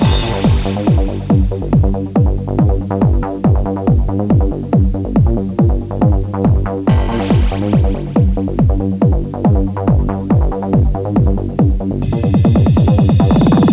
- A 13.73 second clip from a techno/tribal song